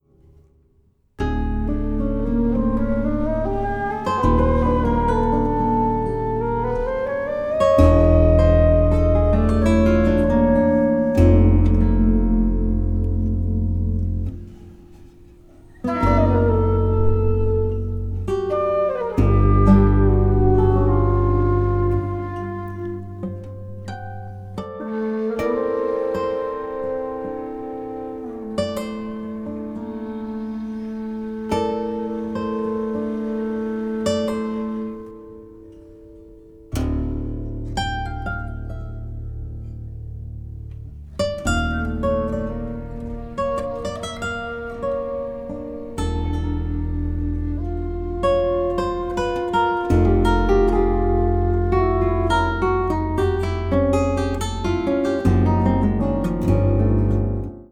Flute, Alto flute, Bansuri flutes
16-string Classical guitar